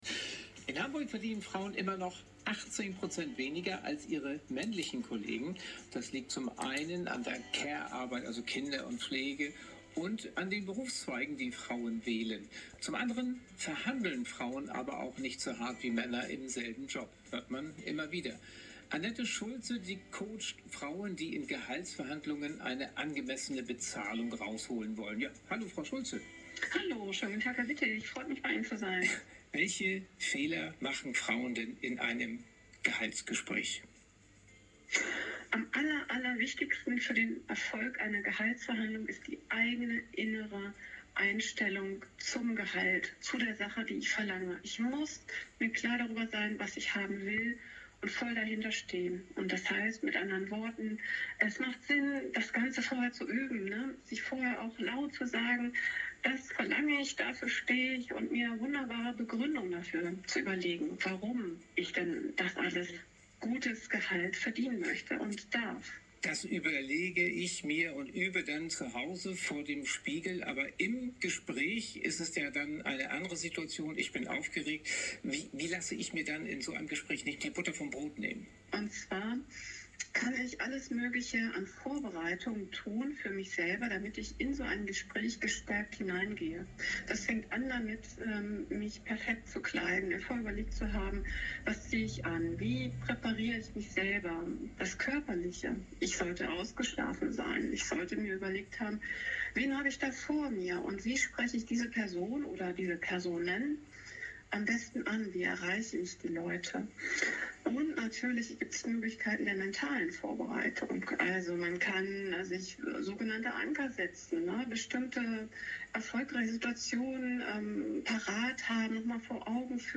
"Equal-Pay-Days" - Radiobeitrag vom 6.